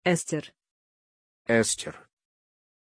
Pronunciation of Ester
pronunciation-ester-ru.mp3